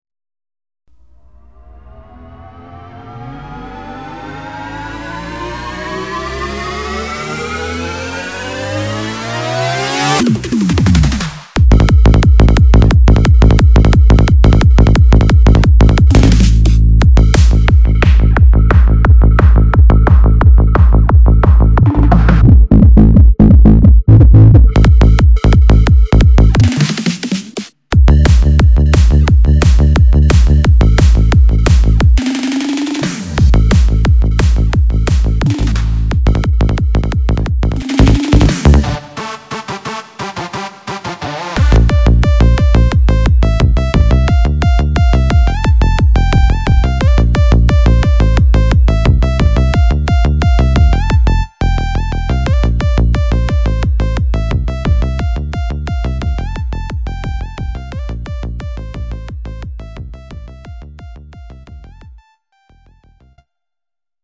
זה פשוט קטע שניגנת עם האורגן